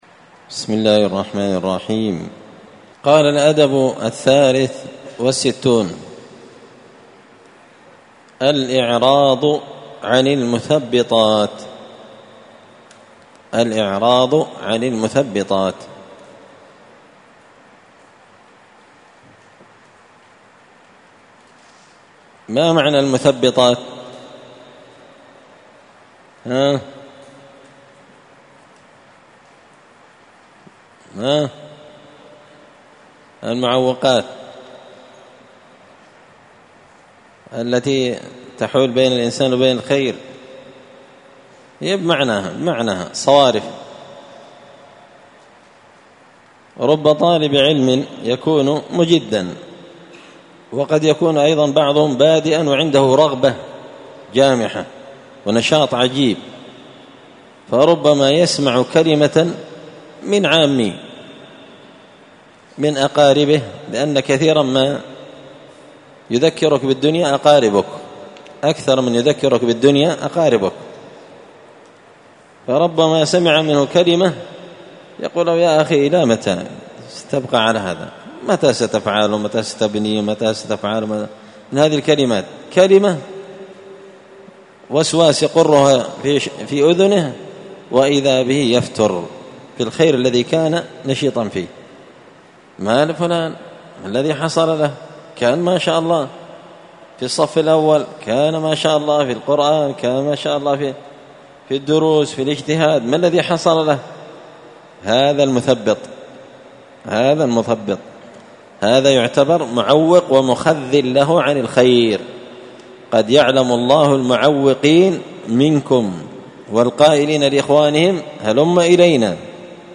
الأثنين 15 ذو الحجة 1444 هــــ | الدروس، النبذ في آداب طالب العلم، دروس الآداب | شارك بتعليقك | 14 المشاهدات